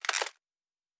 Plastic Foley 03.wav